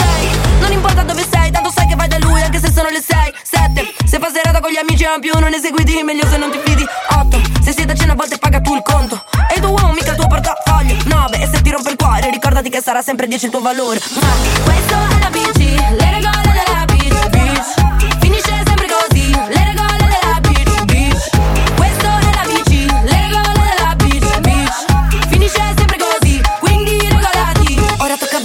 Genere: ,pop,trap,dance,rap,remix,italiana,hit